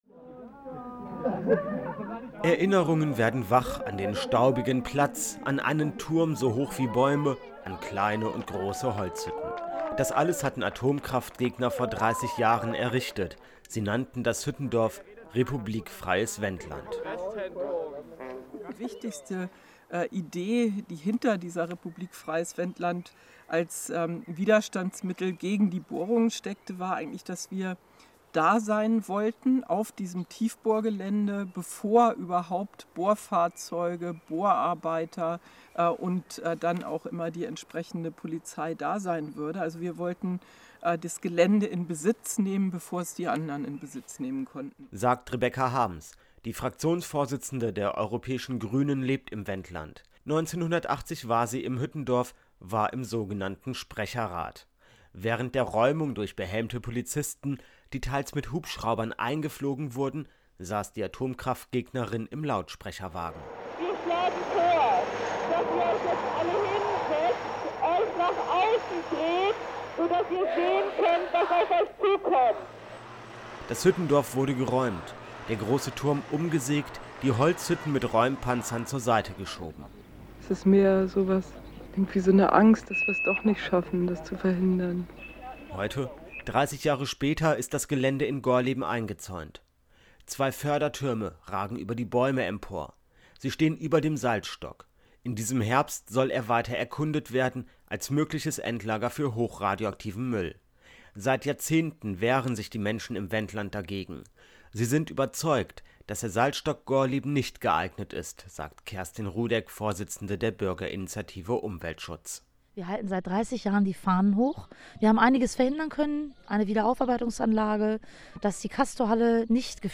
Interview mit Rebecca Harms zum Protestwochenende in Gorleben
Mitglied des Europäischen Parlaments in der Grünen/EFA Fraktion 2004-2019